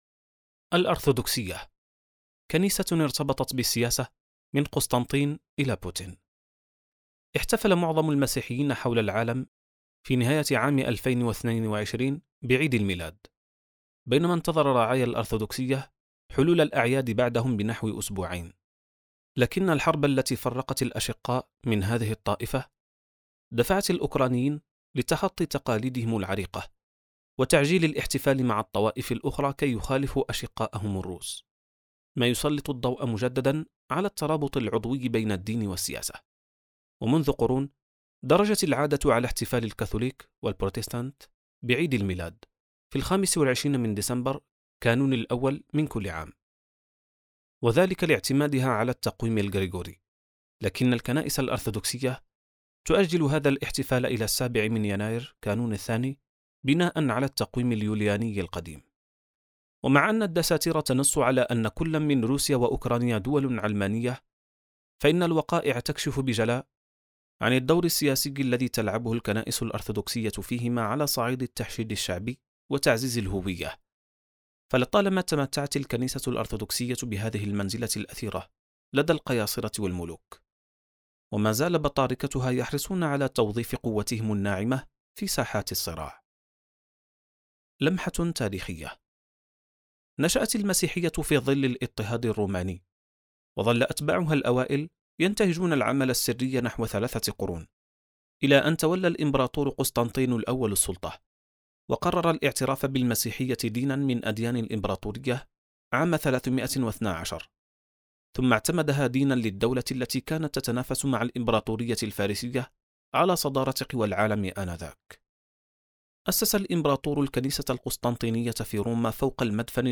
كتاب صوتي | خارطة الطوائف (1519): الأرثوذكسية • السبيل